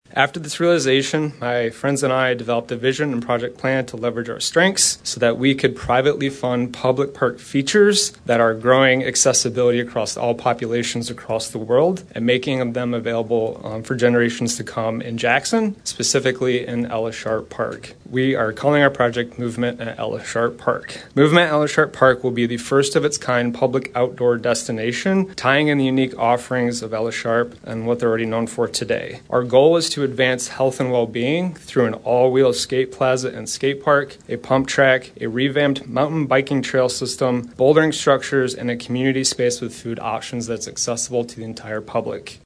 Jackson, Mich. (WKHM) — A three-phase construction project to create recreational and community gathering spaces at Ella Sharp Park was approved by Jackson City Council during their meeting on Tuesday.